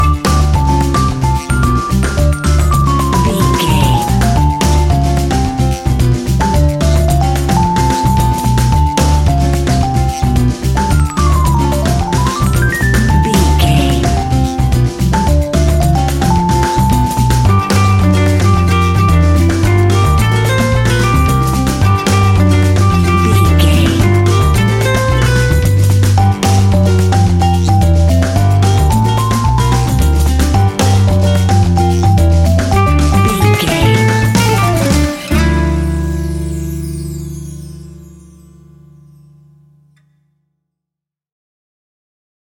An exotic and colorful piece of Espanic and Latin music.
Aeolian/Minor
D
maracas
percussion spanish guitar